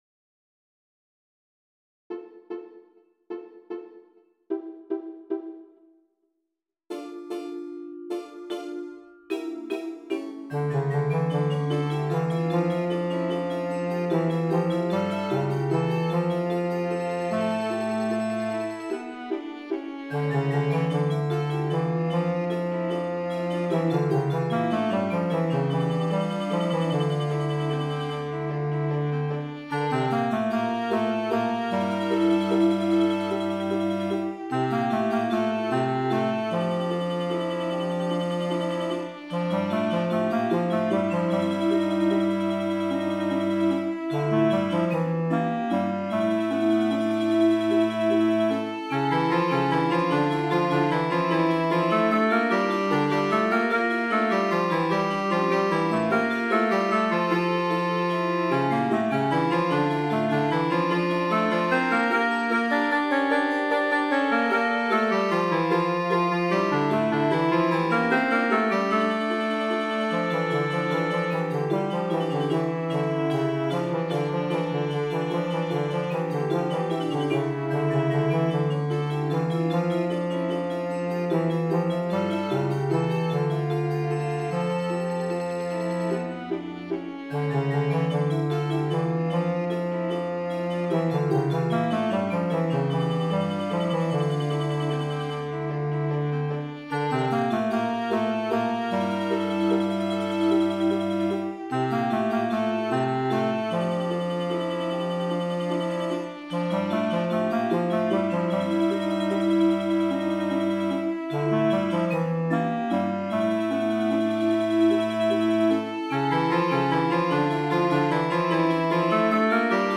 インスト版 3:33/3.3MB 歌詞 <A href="http